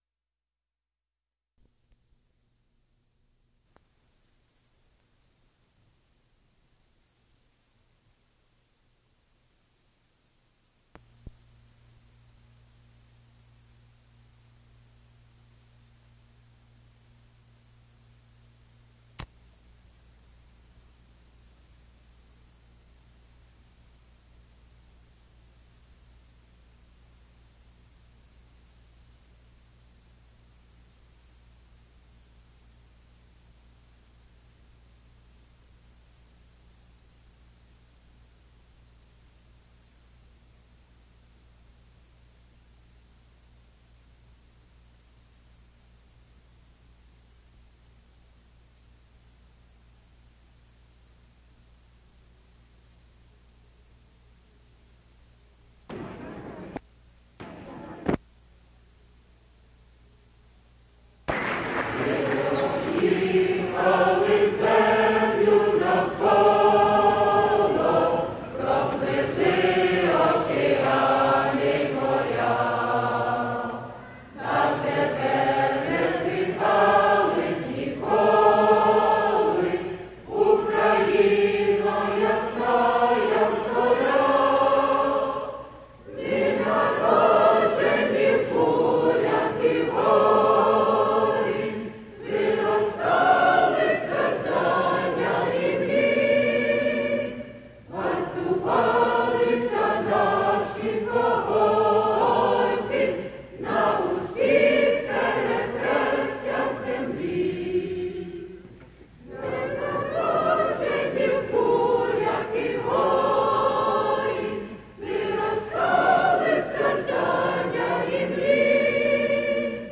ODUM Zustrich concert, 1974